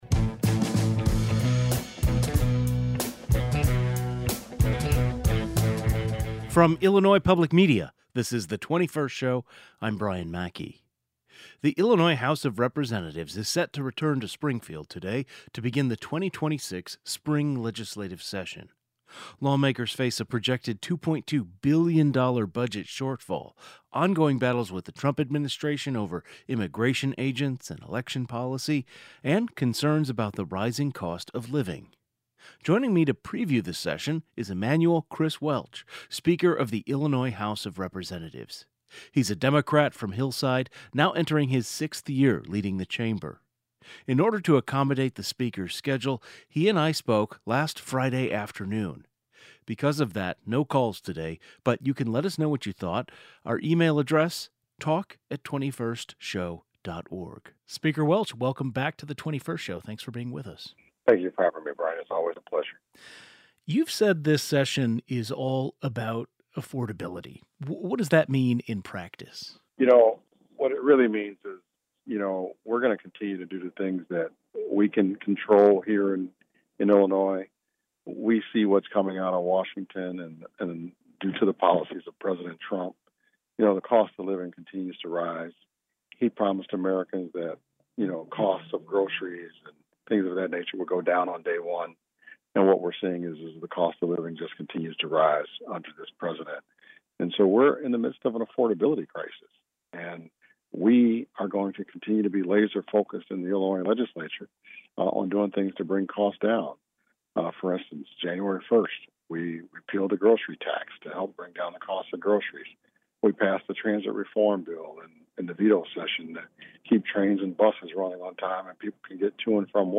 Speaker of the Illinois House of Representatives Emanuel “Chris” Welch joins the program to preview the session. The 21st Show is Illinois' statewide weekday public radio talk show, connecting Illinois and bringing you the news, culture, and stories that matter to the 21st state.